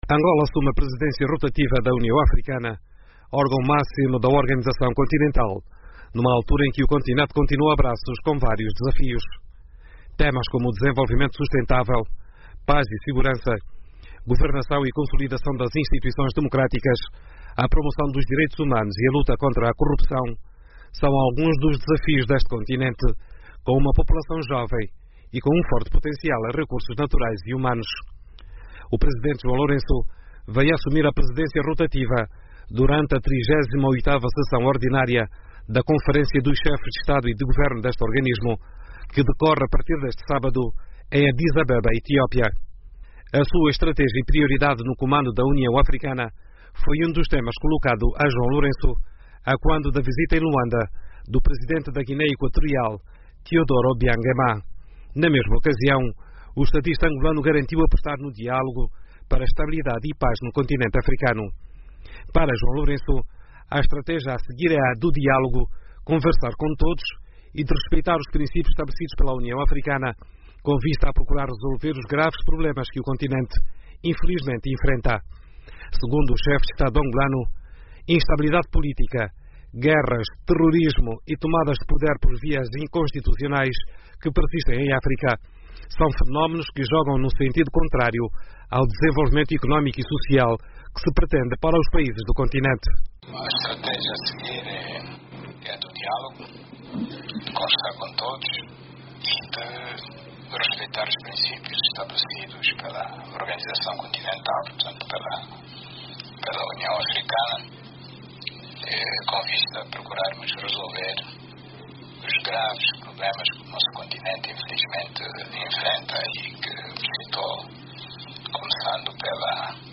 Analistas em Luanda defendem o resgate da credibilidade da União Africana durante a presidência de João Lourenço. Para falar sobre o assunto, ouvimos o analista político